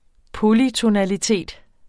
Udtale [ ˈpolytonæliˌteˀd ]